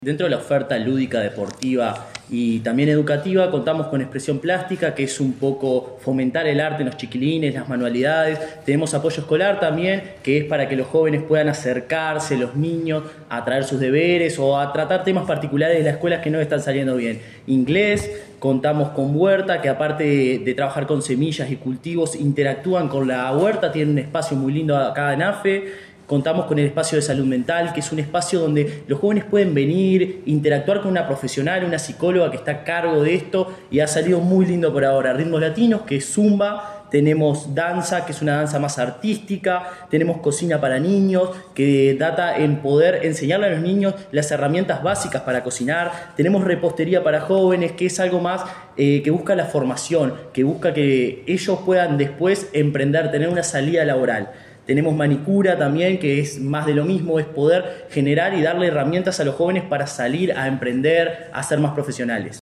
en la ex estación de AFE.